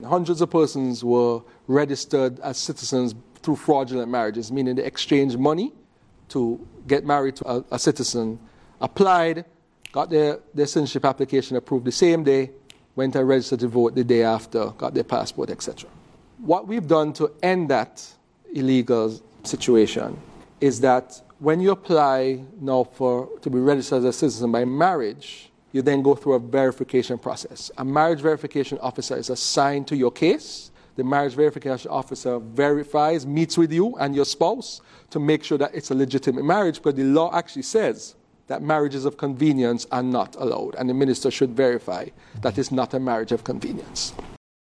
That was Federal Minister of Justice and Legal Affairs, and Attorney General (AG), the Hon. Garth Wilkin who on April 2nd 2025, spoke on fraudulent marriages in Saint Kitts and Nevis.
Attorney General, Garth Wilkin.